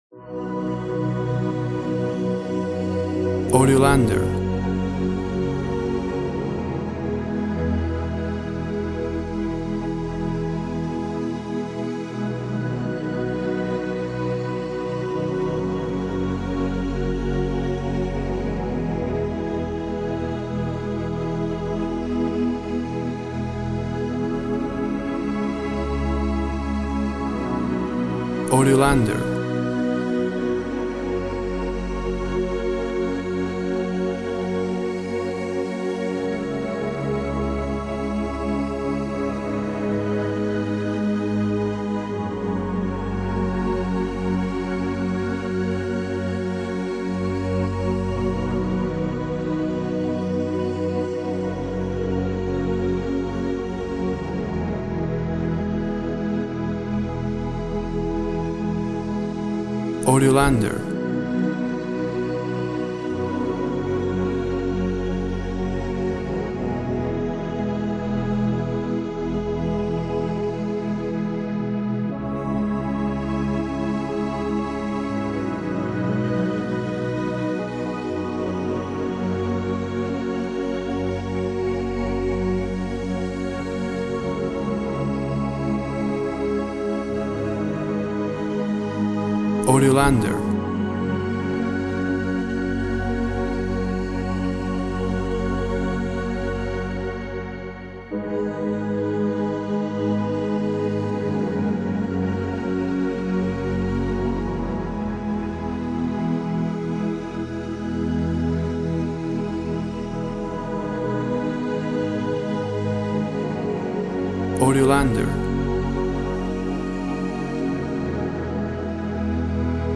Synth strings create a sad and hopeful atmosphere.
Tempo (BPM) 48